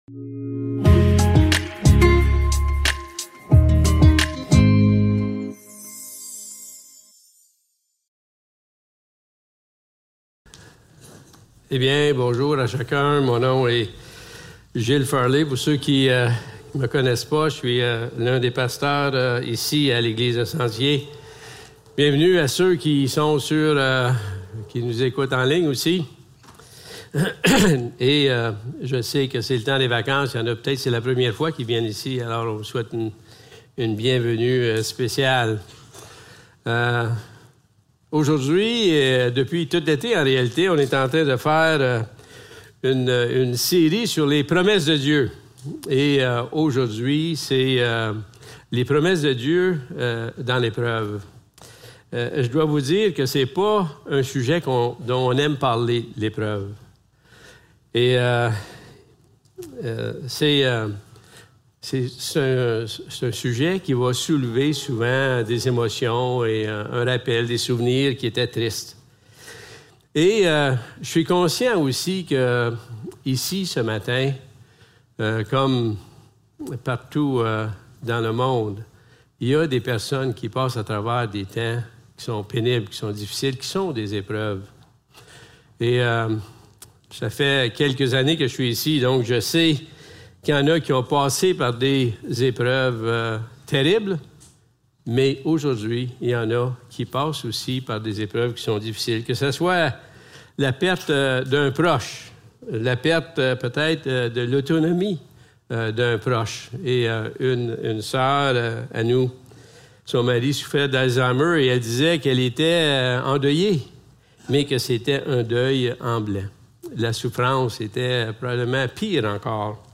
2 Corinthiens 1.3-6 Service Type: Célébration dimanche matin Description